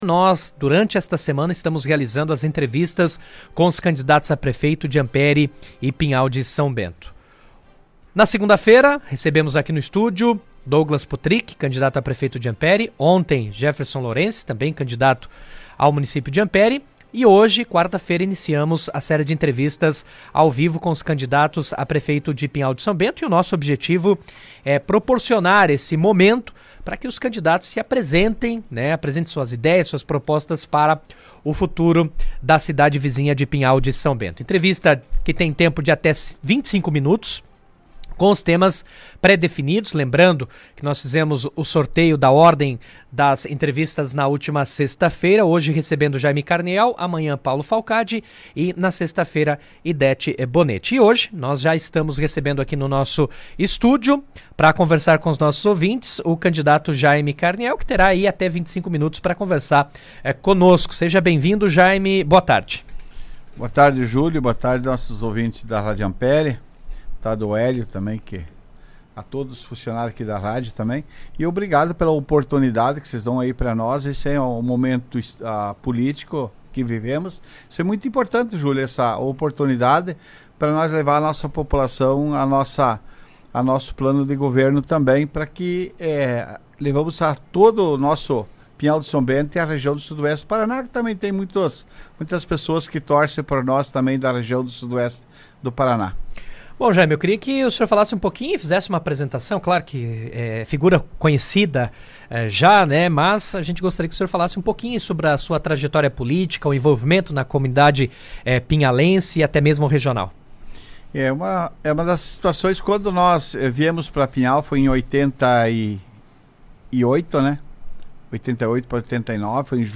Rádio Ampére AM realiza entrevistas com candidatos a Prefeito de Pinhal de São Bento - Rádio Ampere